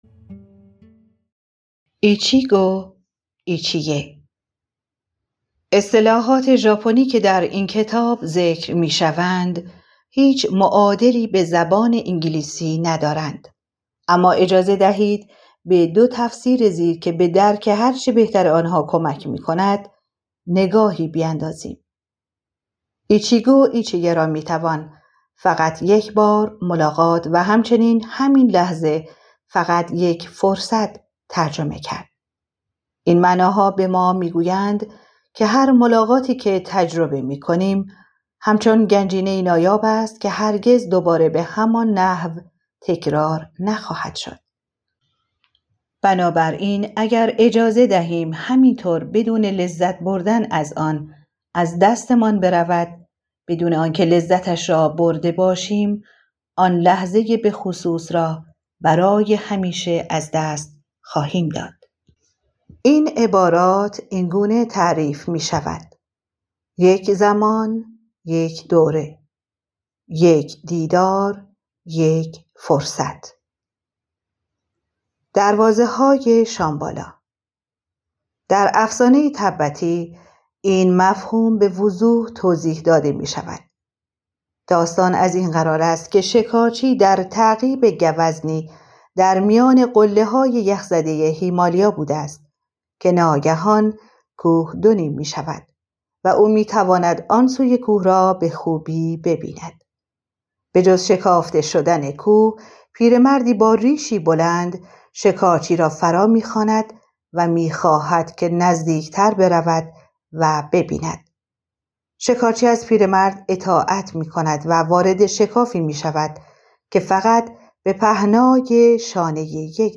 گزیده ای از کتاب صوتی